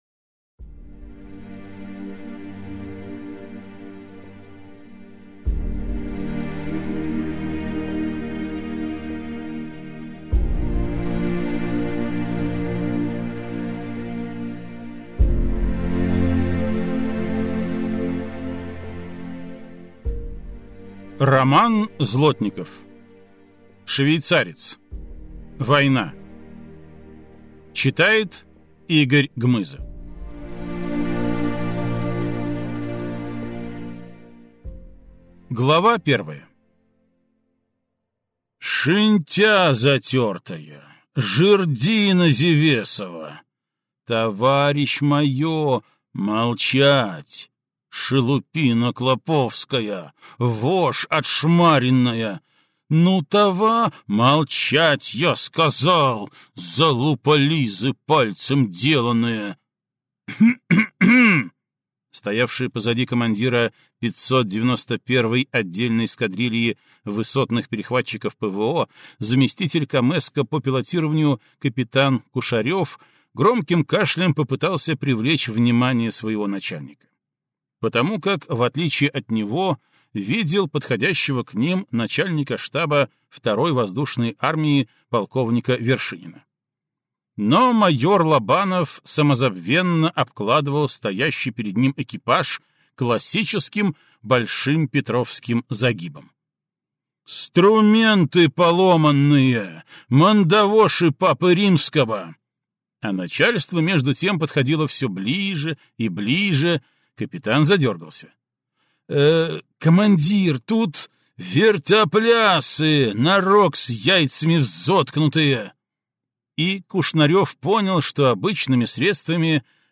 Аудиокнига Швейцарец.